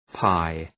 Προφορά
{paı}